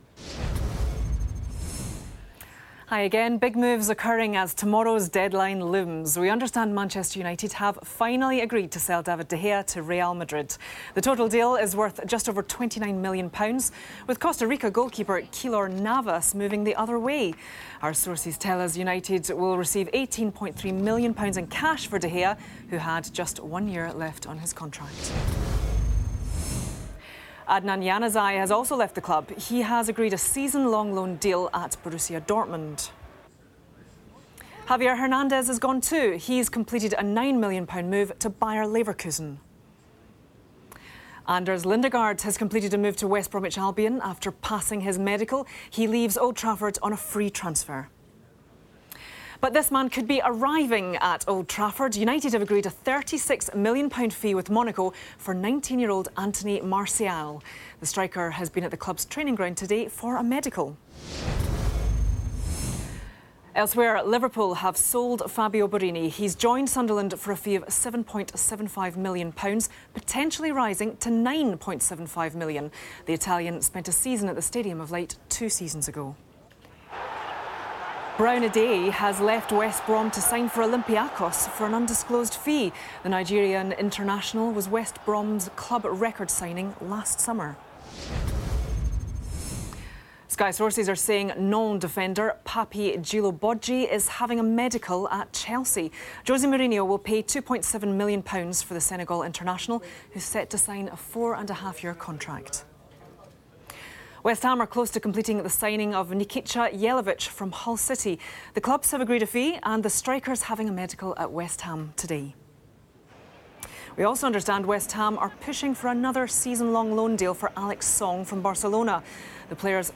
گزارش صوتی اسکای اسپورت ازداغ ترین خبرهای داغ ترین روزنقل و انتقالات(گزارش 12 و 32 دقیقه)